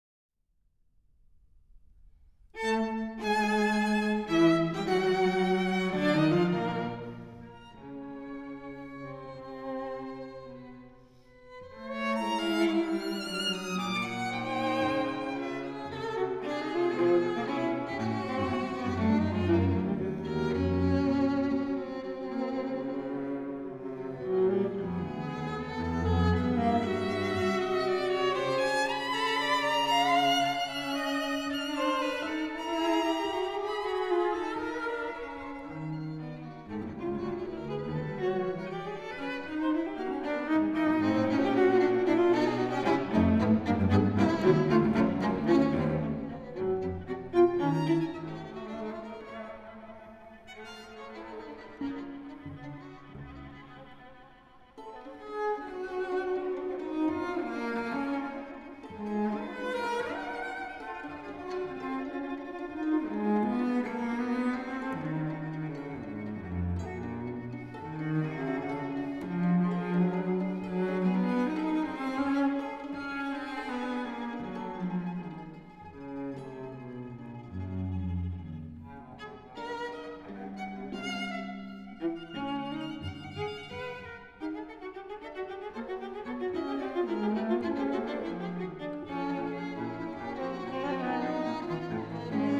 古典音樂、發燒天碟